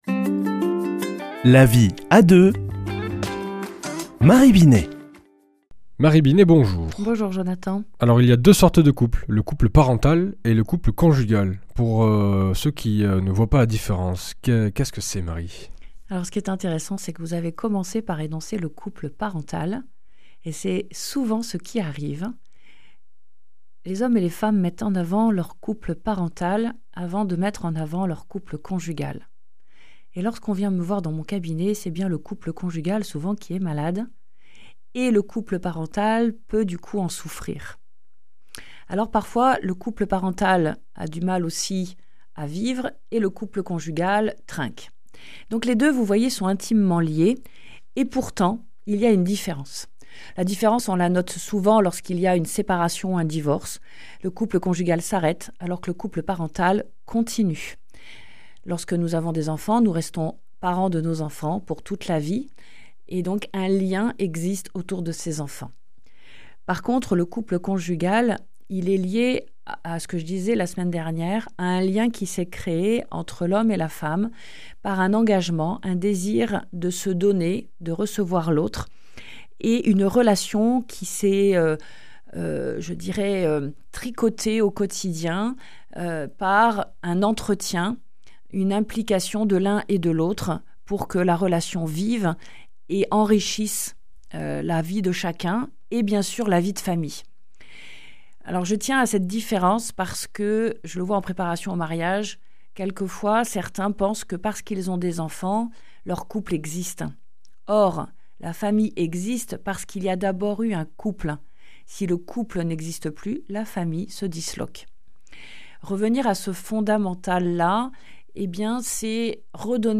mardi 13 mai 2025 Chronique La vie à deux Durée 4 min
Une émission présentée par